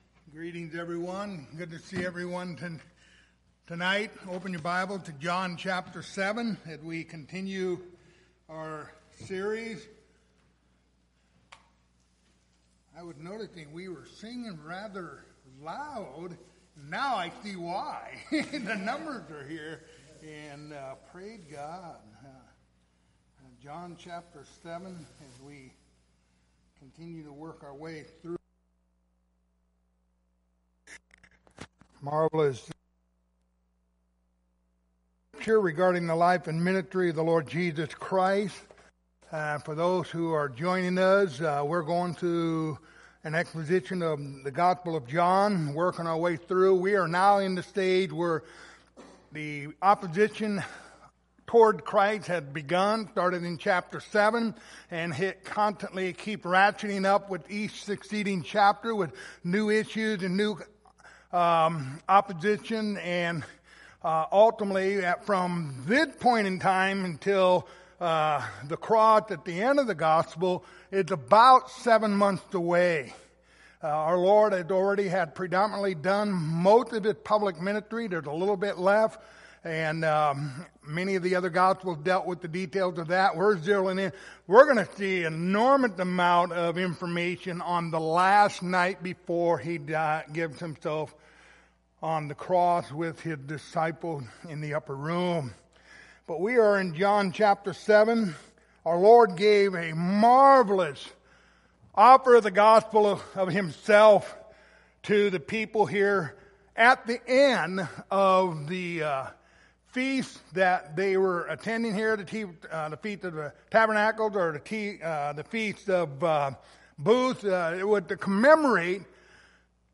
The Gospel of John Passage: John 7:40-44 Service Type: Wednesday Evening Topics